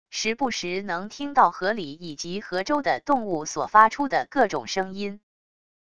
时不时能听到河里以及河周的动物所发出的各种声音wav音频